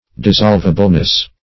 Dis*solv"a*ble*ness, n.